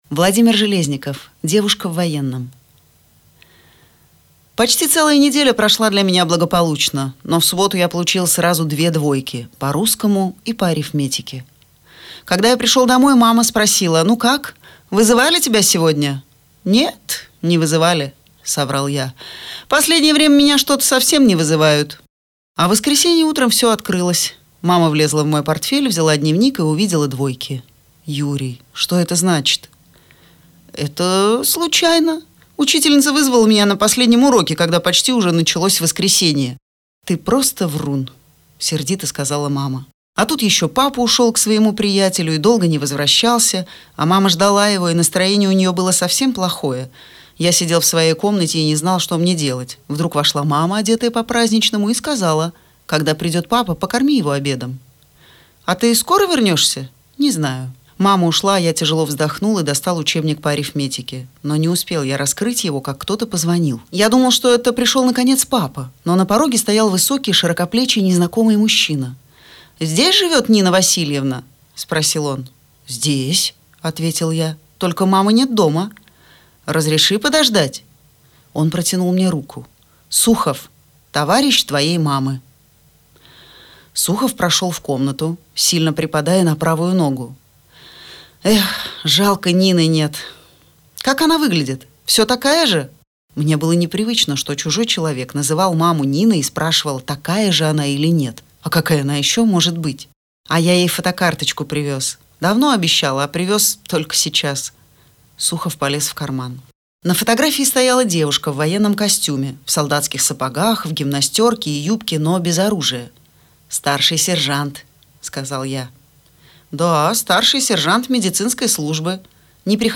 Девушка в военном - аудио рассказ Железникова - читать онлайн